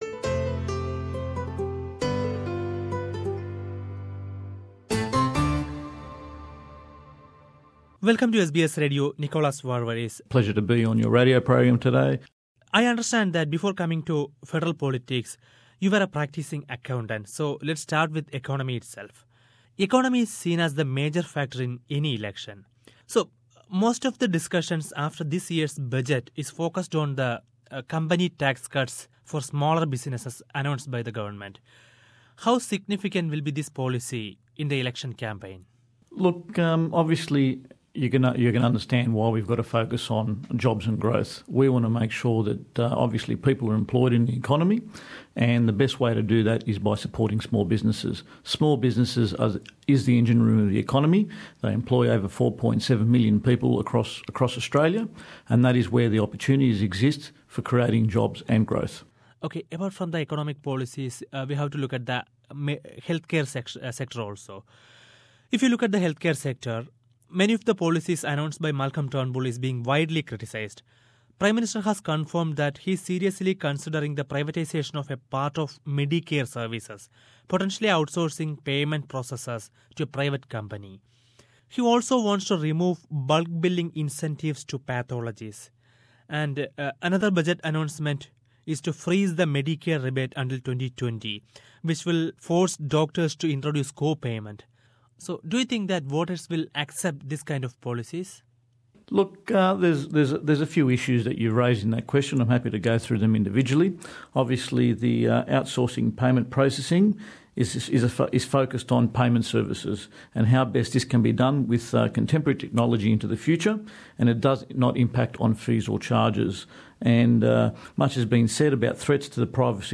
Interview wiht Nickolas Varvaris, Liberal MP
Liberal Party sitting MP and candidate from Barton Nickolas Varvaris talks to SBS Radio